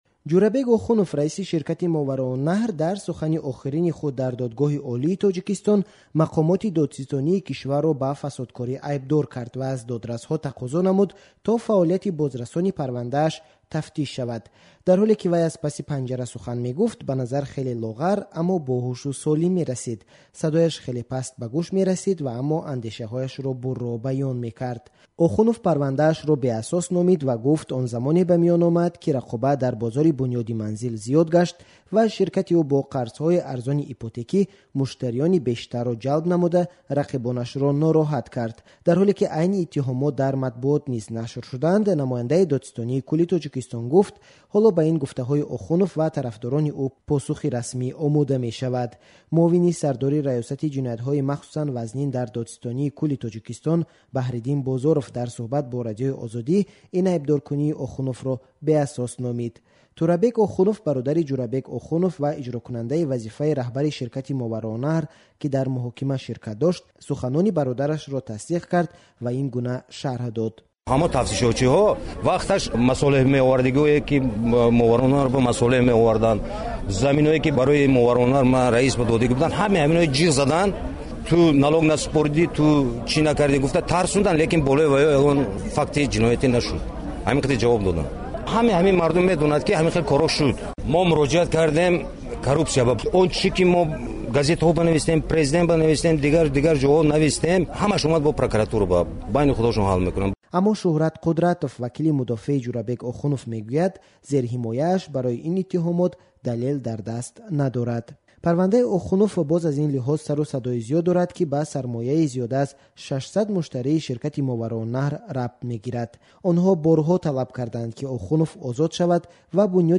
дар толори Додгоҳи олӣ